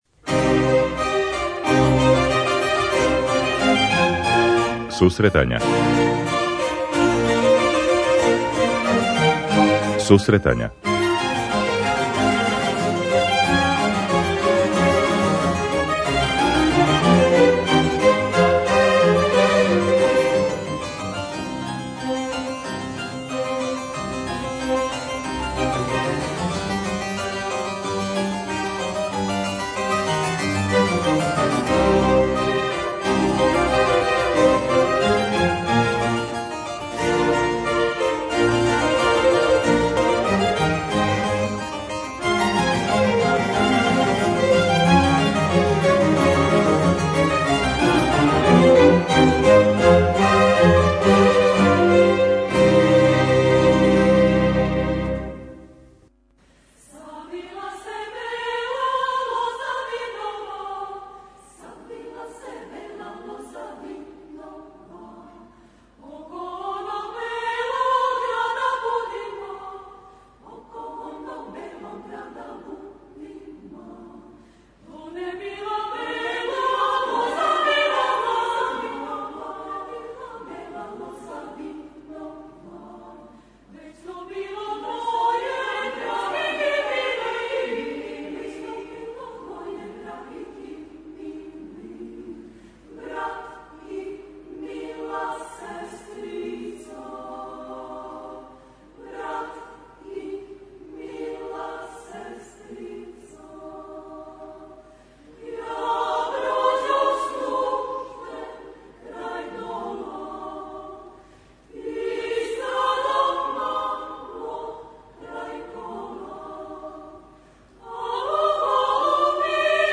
Емисија се реализује из Ниша, где се одржава фестивал 24. Интернационалне хорске свечаности.